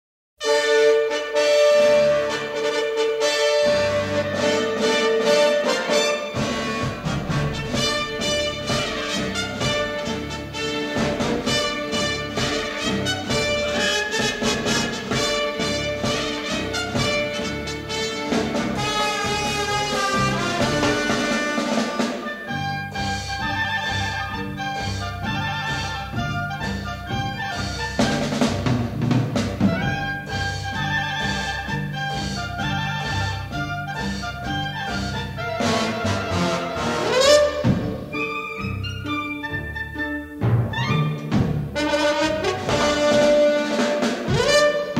released in stereo in 1959